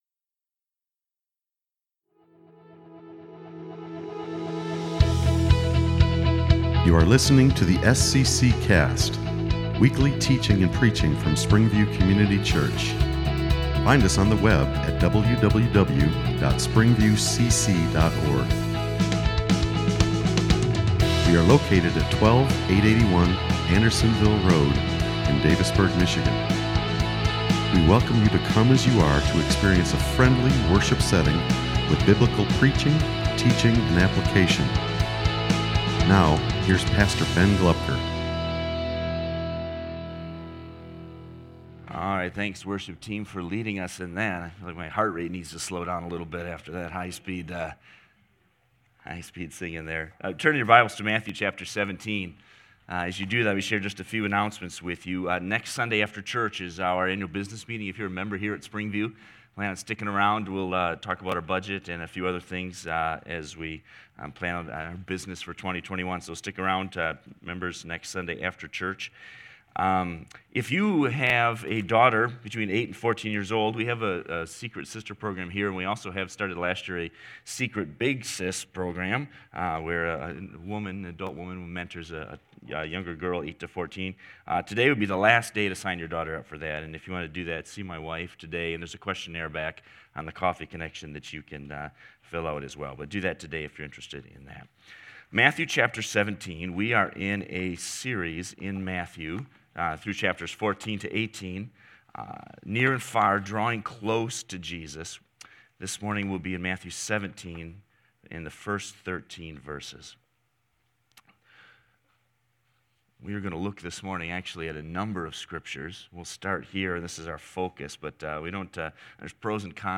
Sermons | SPRINGVIEW COMMUNITY CHURCH